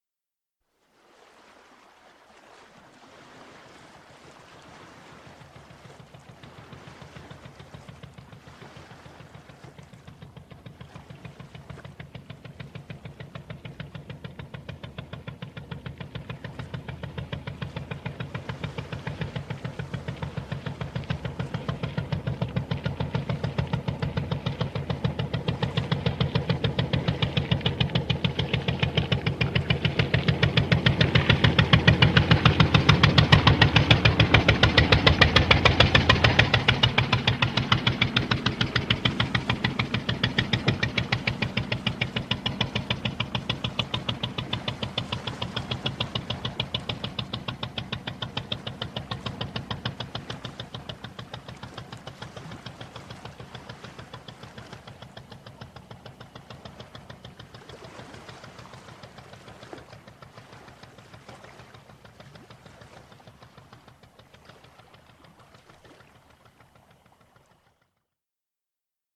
The rhythm of Japanese is even pace.
After the end of the Samurai era, when a Westerner heard Japanese for the first time, he described the sound as a 'puffing steamboat.'
Puffing away steamboat
steamboat.mp3